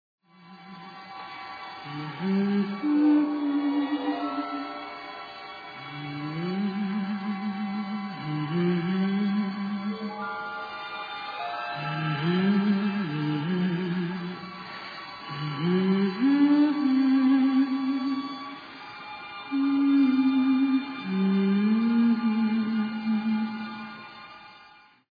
gently jazzy
spooky mysterioso take
moaning, witchlike vocal